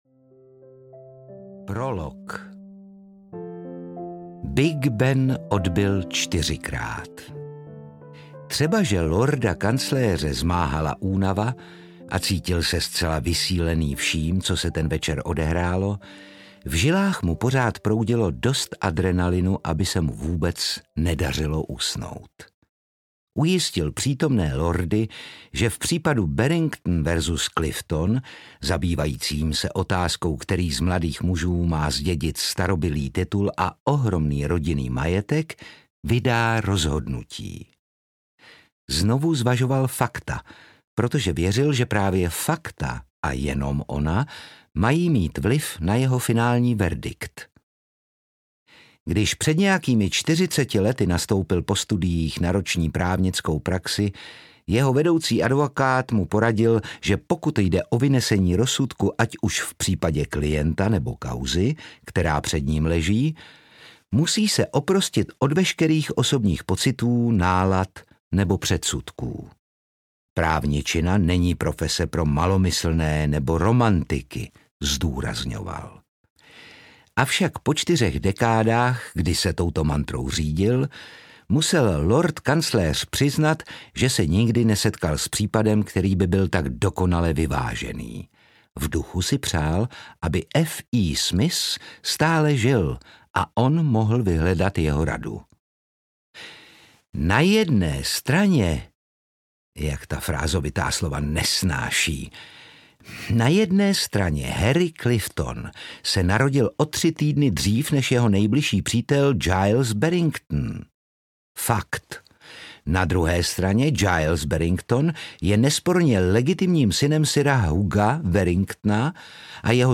Přísně střežené tajemství audiokniha
Ukázka z knihy
• InterpretOtakar Brousek ml.
prisne-strezene-tajemstvi-audiokniha